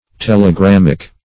Search Result for " telegrammic" : The Collaborative International Dictionary of English v.0.48: Telegrammic \Tel`e*gram*mic\, a. Pertaining to, or resembling, a telegram; laconic; concise; brief.